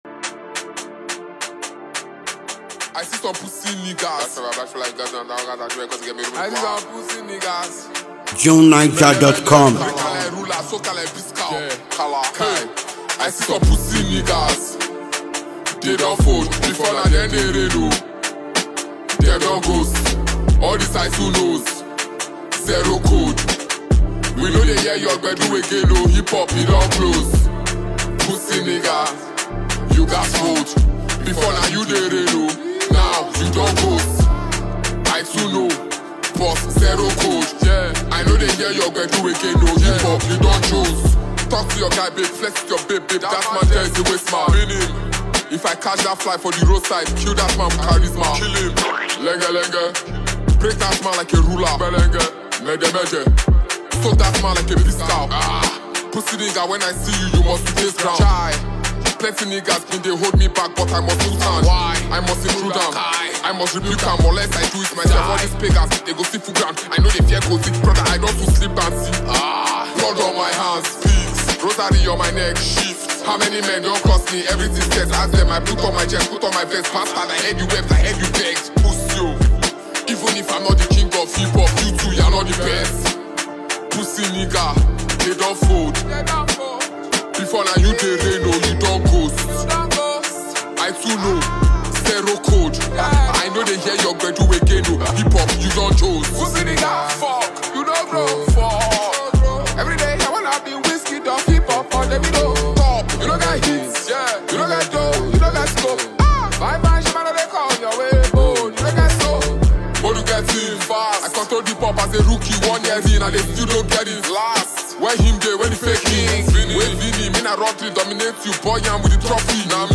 It’s a song that will give your playlist a new, upbeat vibe.